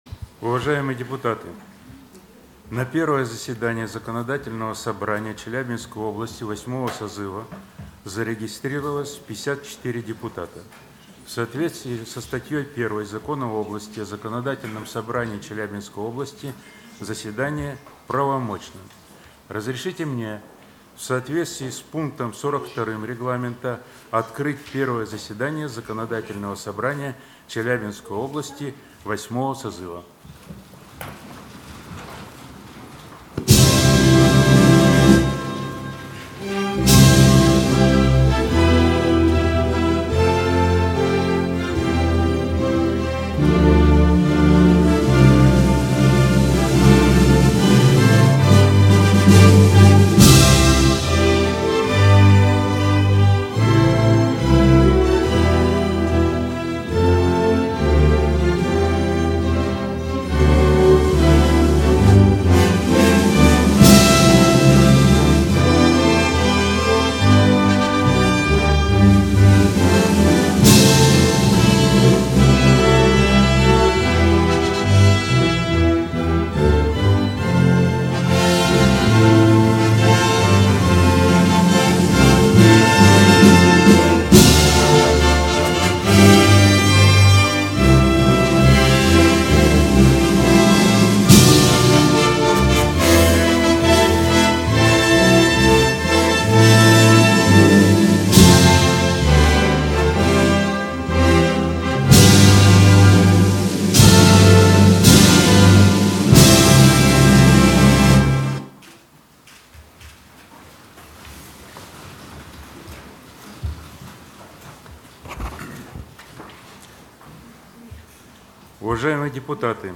Первое заседание Законодательного Собрания Челябинской области VIII созыва